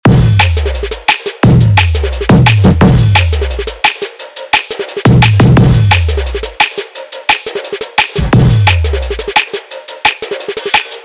LOOP PACK